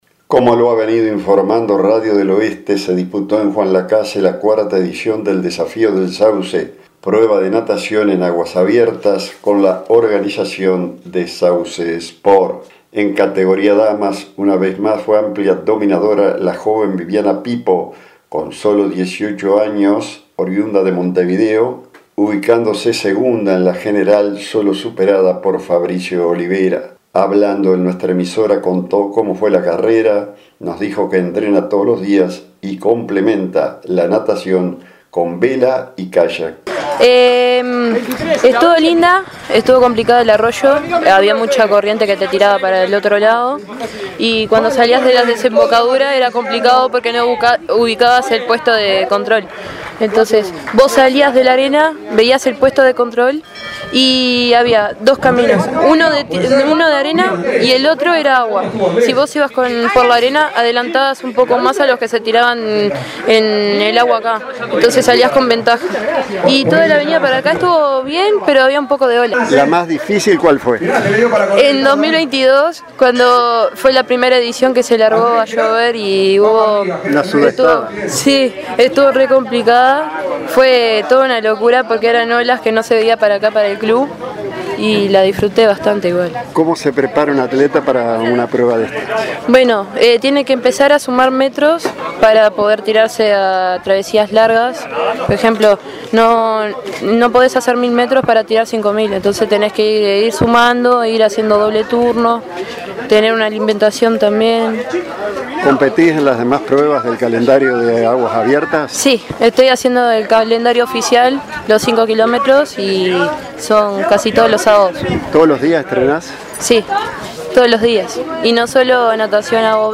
Hablando en nuestra emisora contó como fue la carrera ,entrena todos los días complementando la natación con Vela y Kayak.